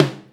snare 6.wav